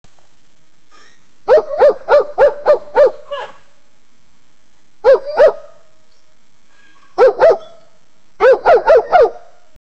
Barking of puppy
barking-of-puppy-rczqshvg.wav